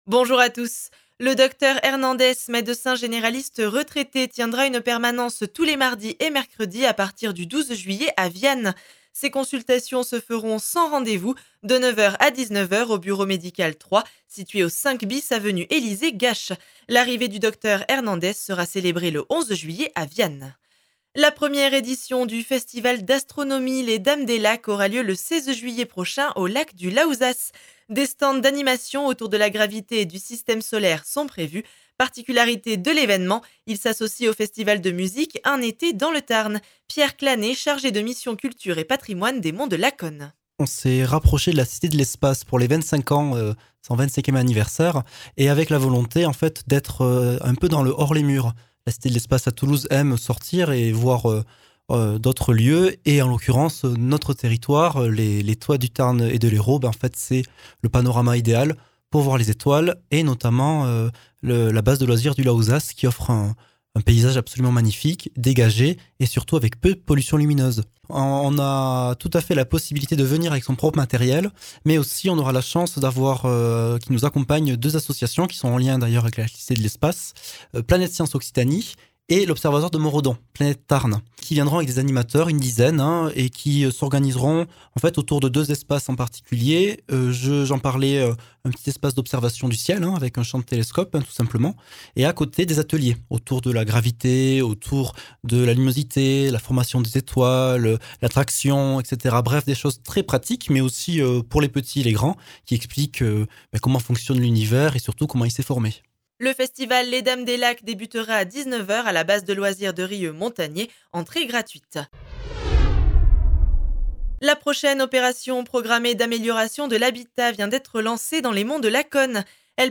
Flash Monts de Lacaune 07 juillet 2022